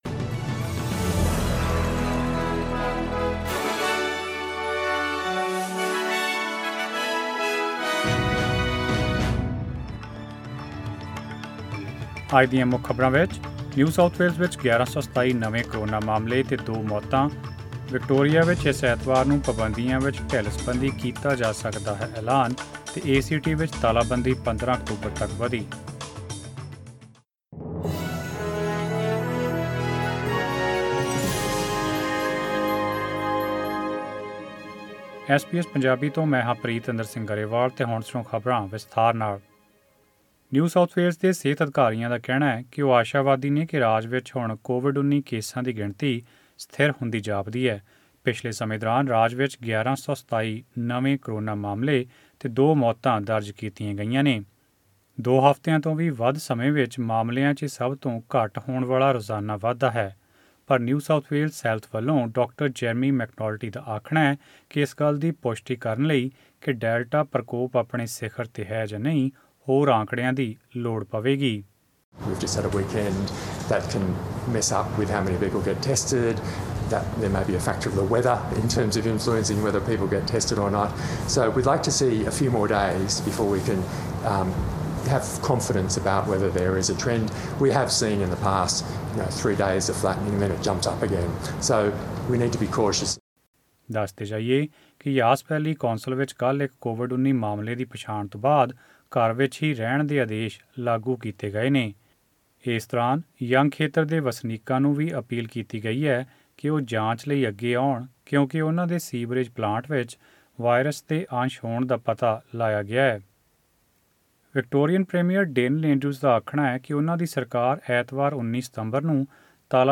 Click on the player at the top of the page to listen to the news bulletin in Punjabi.